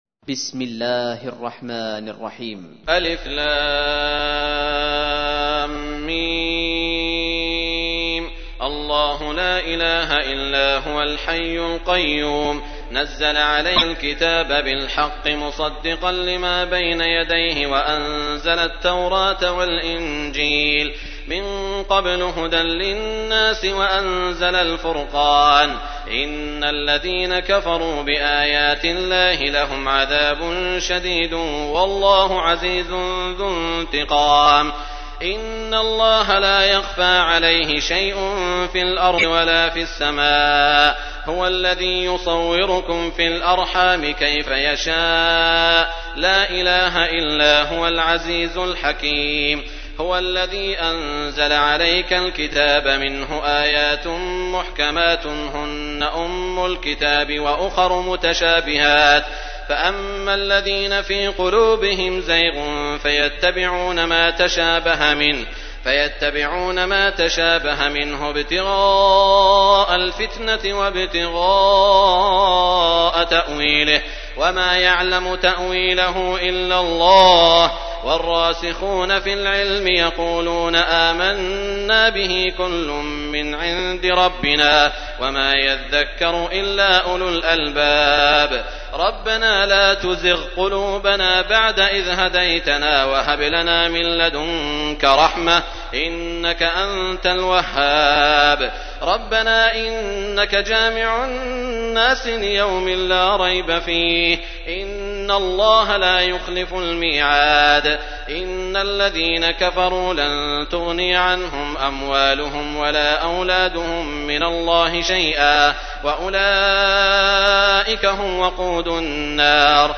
تحميل : 3. سورة آل عمران / القارئ سعود الشريم / القرآن الكريم / موقع يا حسين